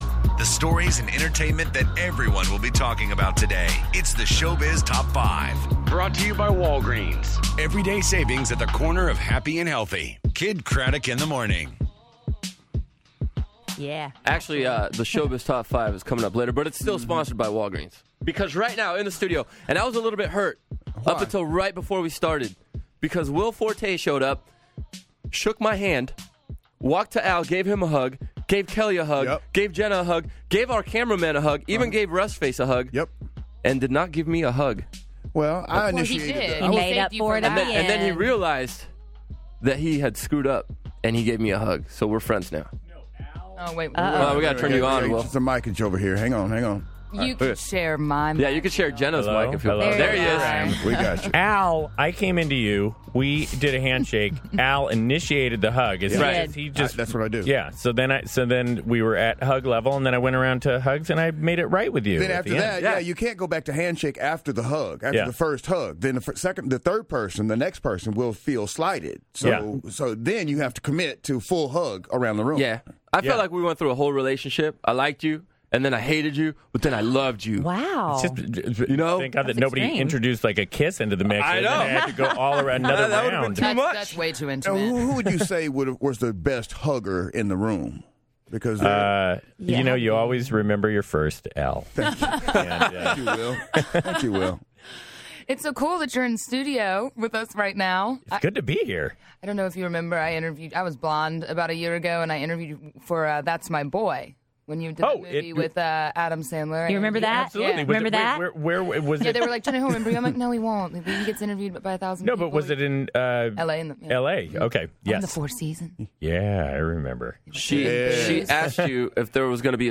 Will Forte Interview
Kidd Kraddick in the Morning interviews Will Forte!